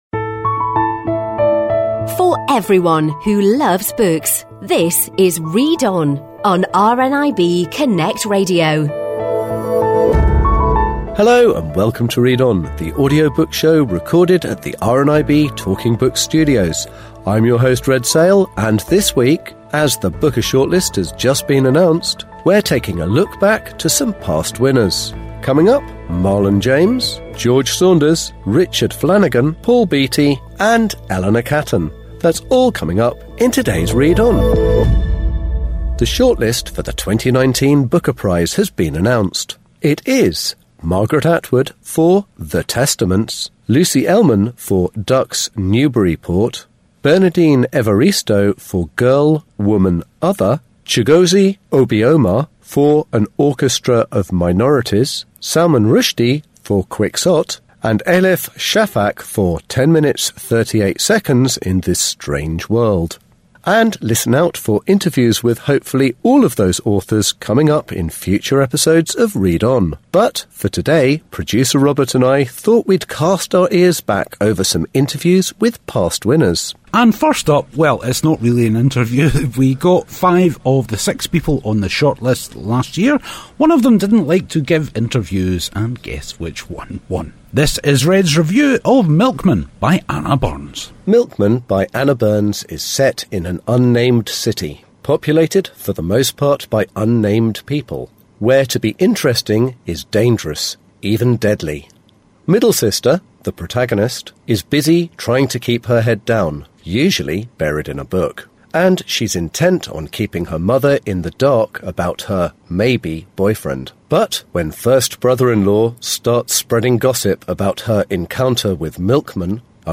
all interviews recorded before the winner of that year was announced.